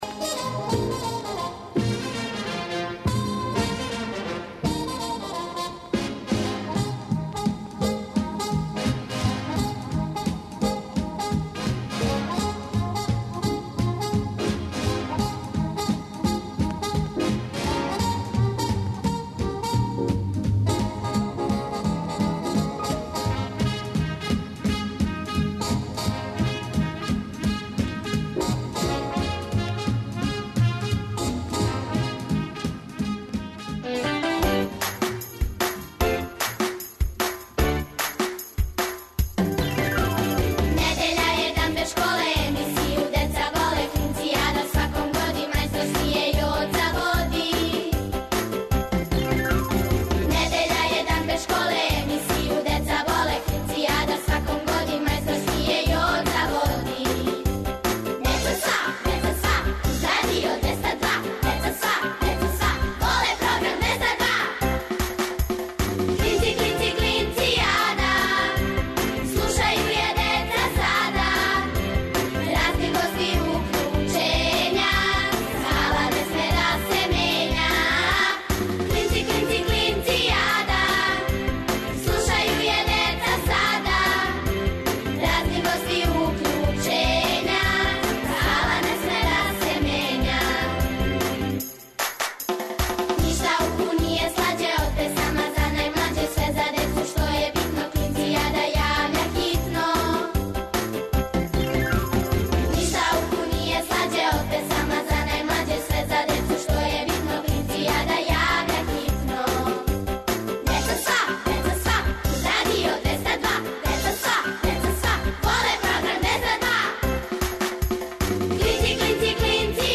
О деци за децу, емисија за клинце и клинцезе, и све оне који су у души остали деца. Сваке недеље уживајте у великим причама малих људи, бајкама, дечјим песмицама.